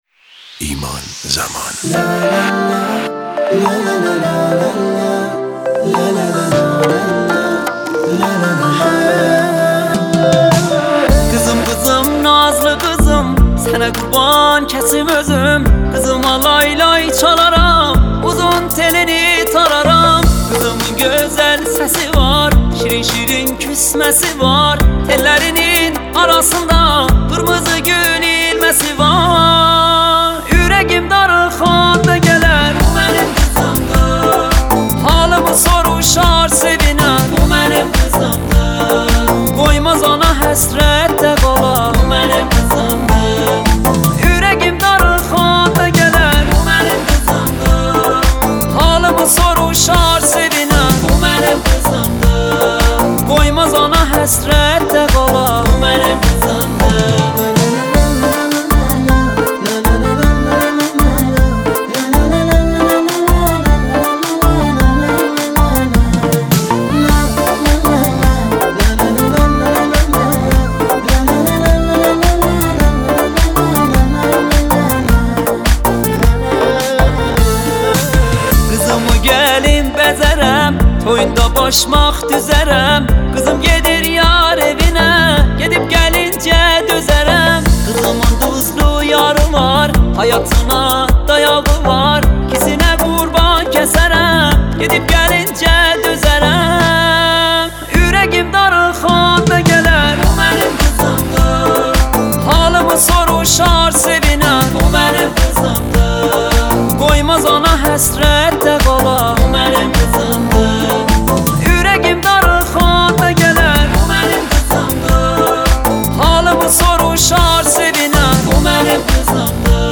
برچسب هاپرطرفدار ، تک آهنگ
با صدای مرد